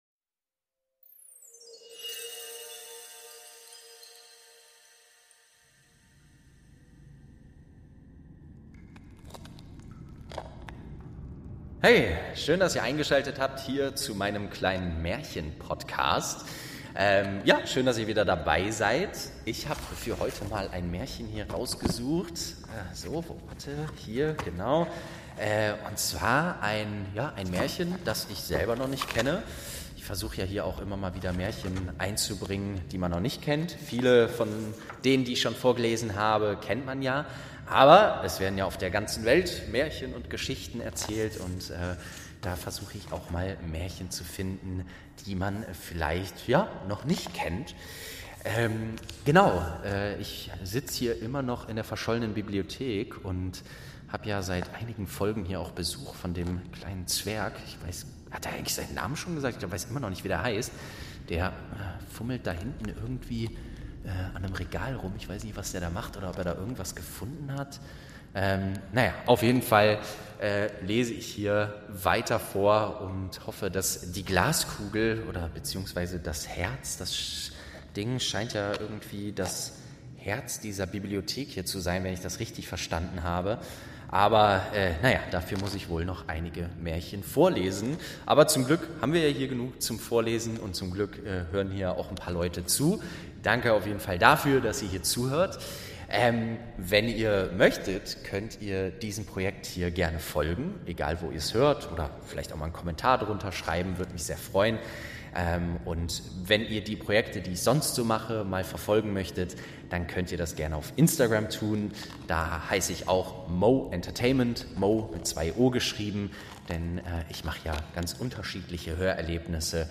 8. Der Zaubertopf und die Zauberkugel| Staffel 2 ~ Märchen aus der verschollenen Bibliothek - Ein Hörspiel Podcast